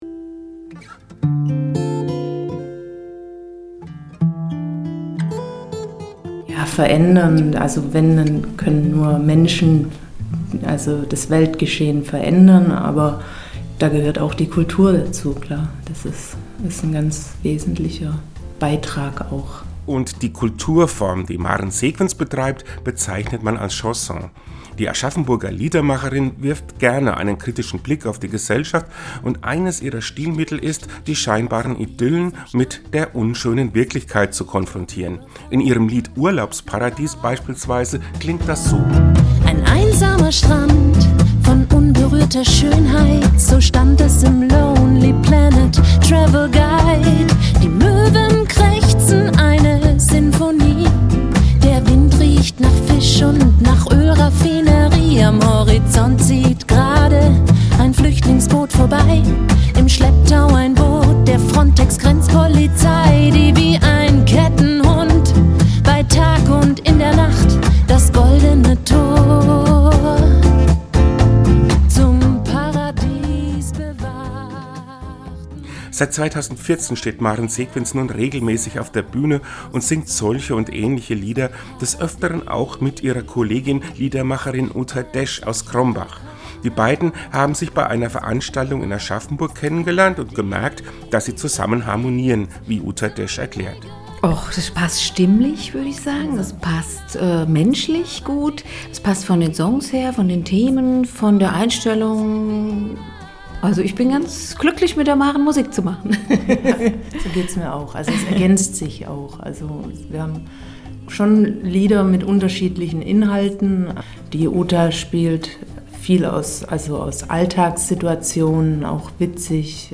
Den Radiobeitrag finden Sie unten als Download!